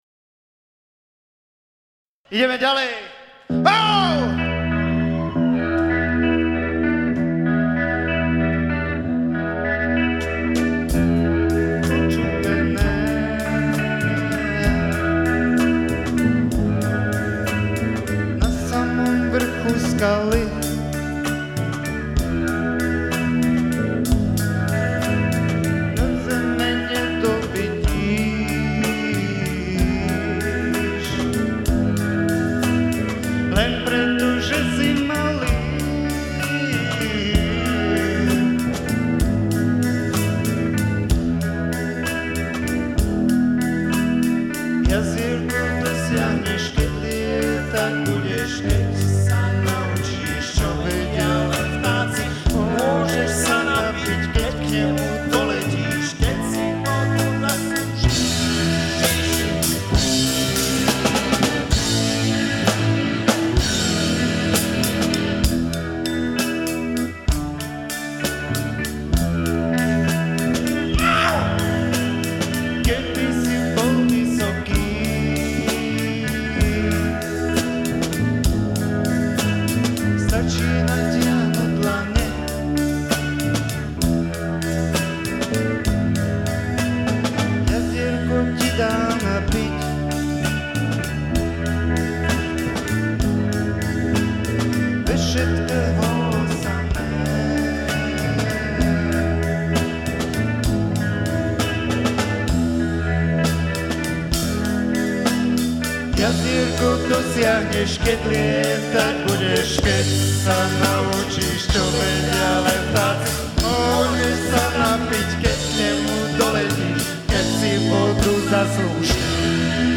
Nahráte: Live Kino Hviezda Trenčín 16.12.2000